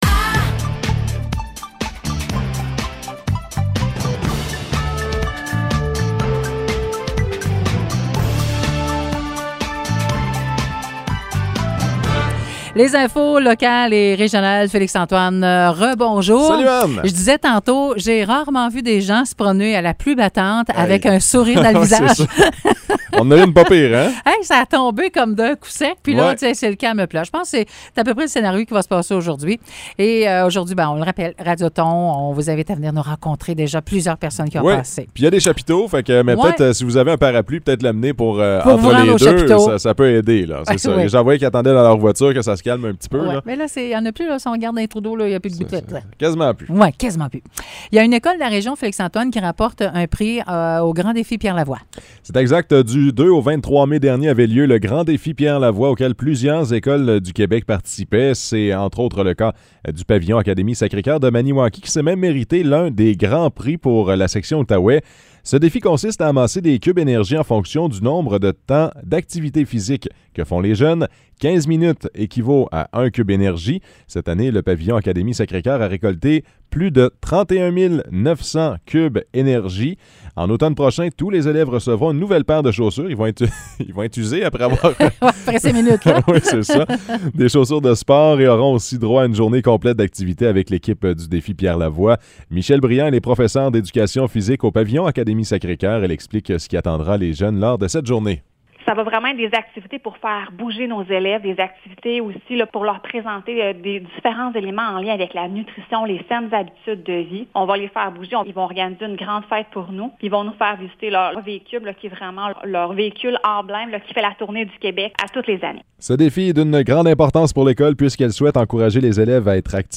Nouvelles locales - 10 juin 2022 - 10 h